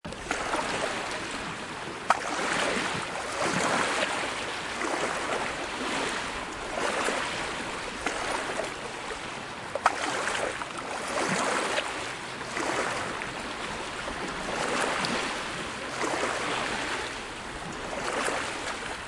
Download Wave sound effect for free.
Wave